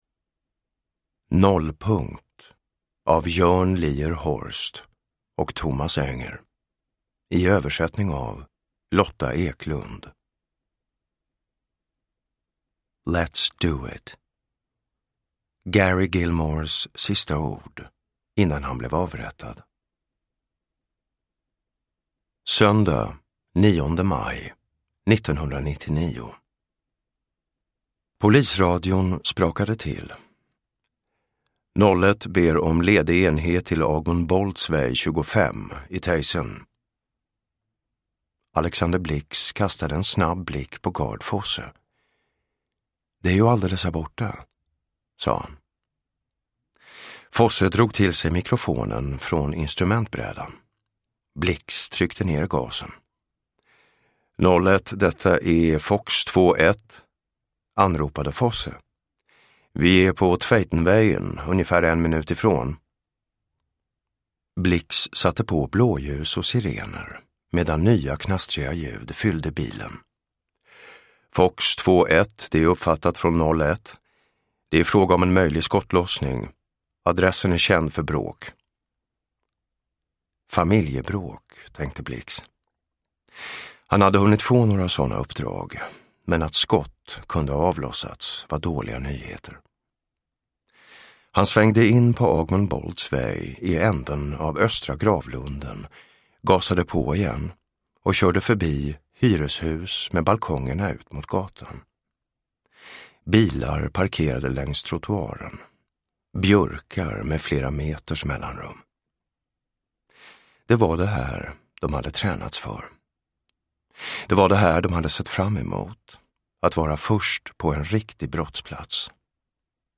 Nollpunkt – Ljudbok – Laddas ner
Uppläsare: Jonas Malmsjö